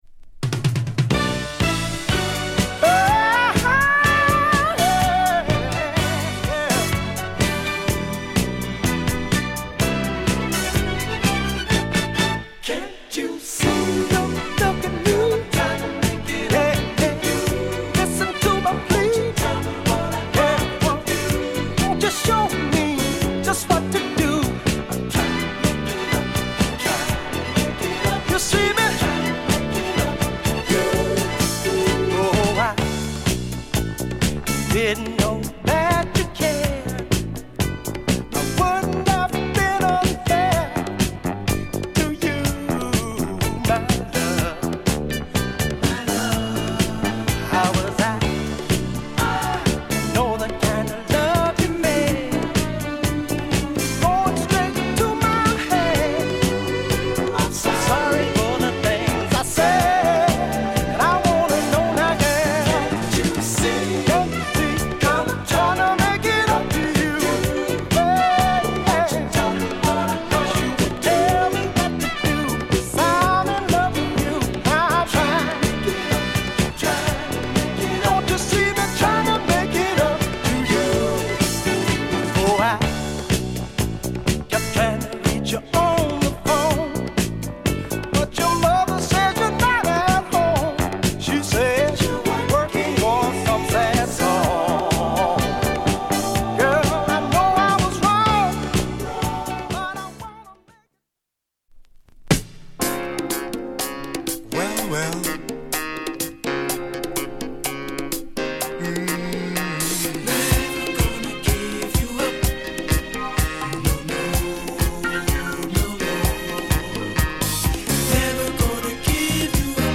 SOUL DISCO
Great Philly Soul!!
夢見心地なフィリーダンサー